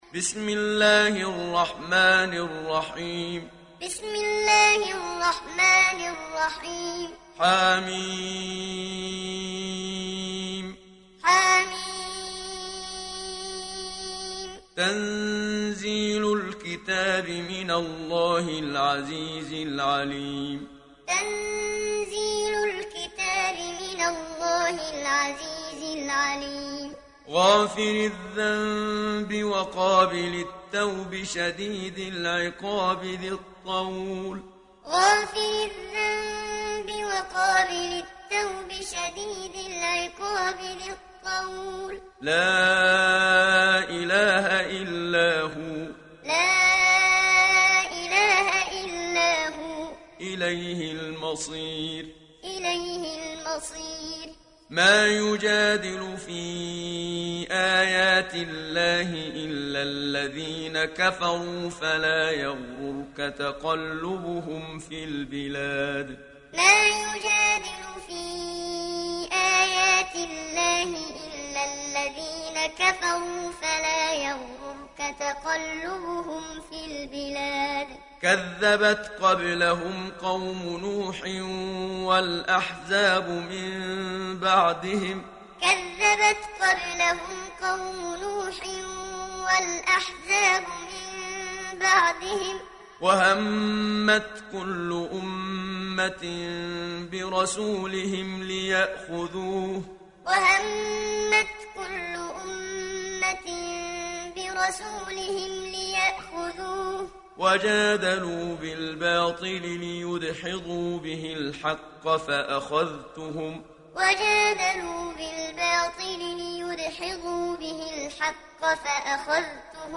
دانلود سوره غافر mp3 محمد صديق المنشاوي معلم روایت حفص از عاصم, قرآن را دانلود کنید و گوش کن mp3 ، لینک مستقیم کامل
دانلود سوره غافر محمد صديق المنشاوي معلم